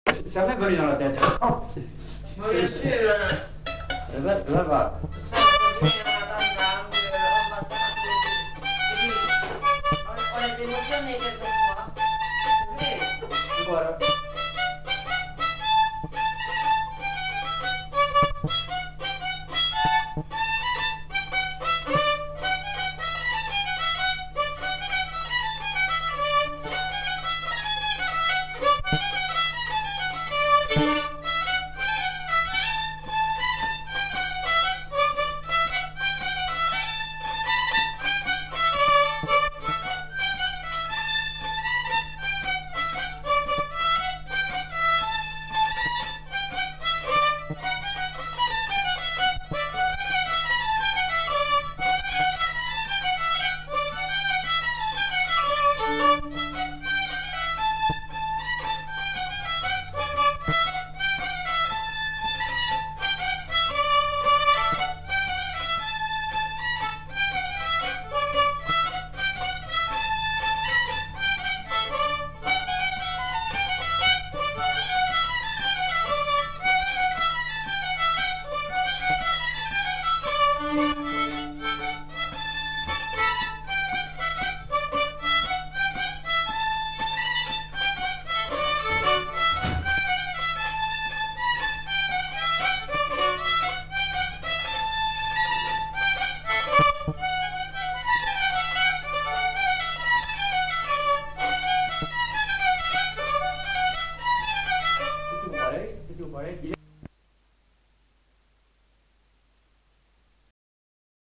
Lieu : Casteljaloux
Genre : morceau instrumental
Instrument de musique : violon
Danse : rondeau
Notes consultables : 2 violons.